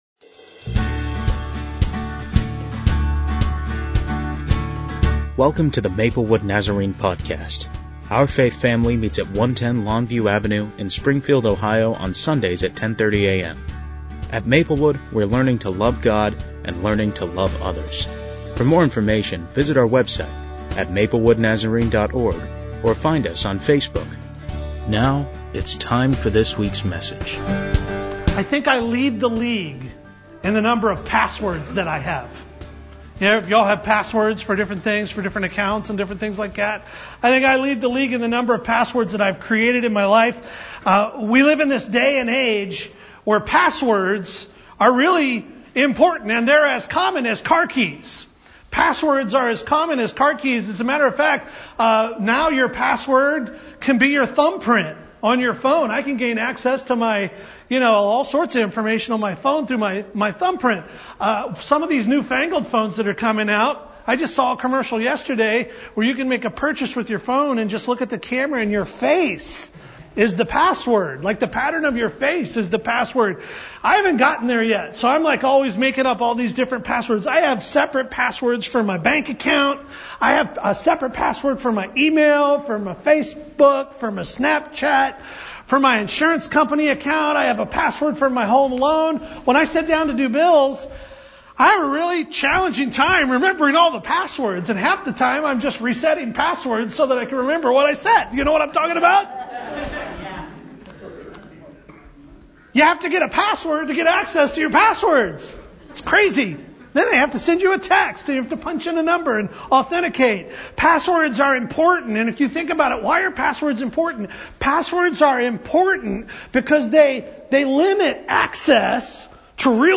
as Maplewood celebrates Palm Sunday